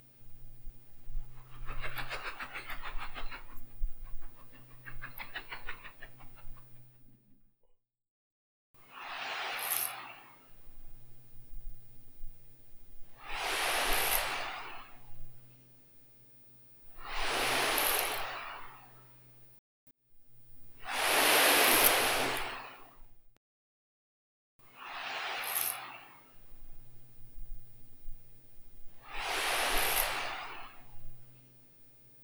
Este instrumento musical aerófono libre está diseñado en base a la Firringila, un instrumento popular pensado como juego infantil sonoro.